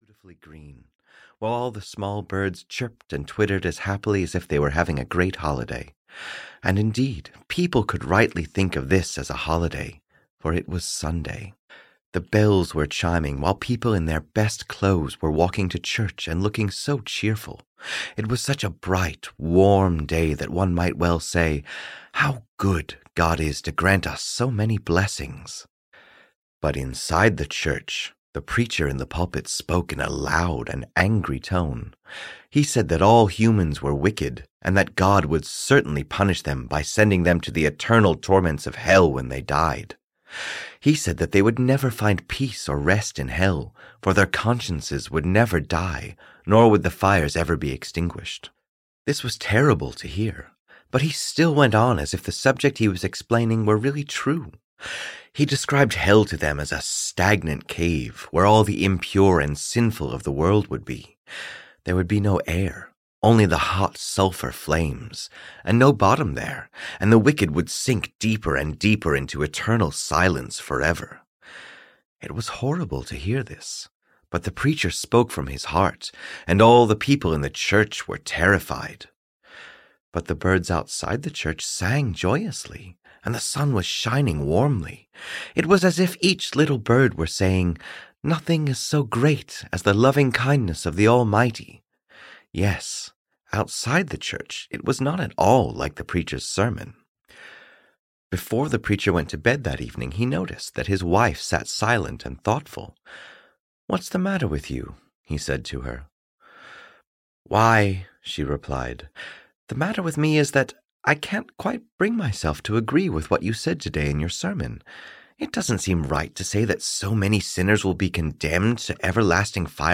A Story (EN) audiokniha
Ukázka z knihy